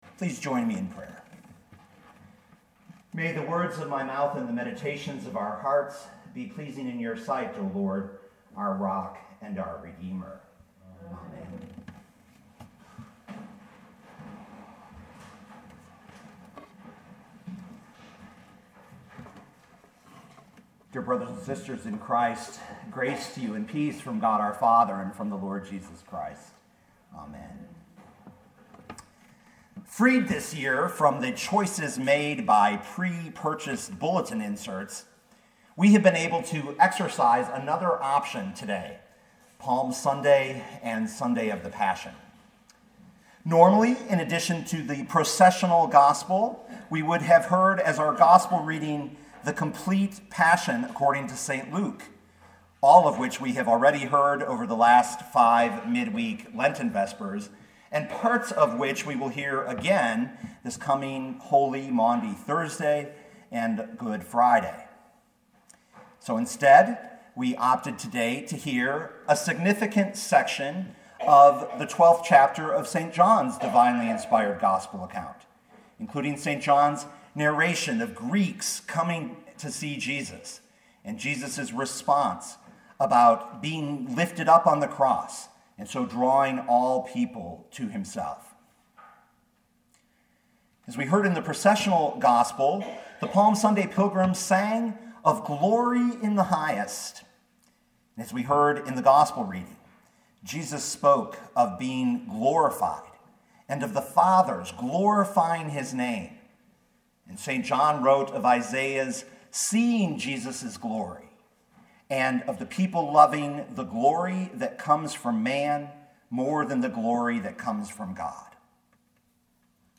the-glory-we-seek-on-palm-sunday-is-revealed-on-the-cross.mp3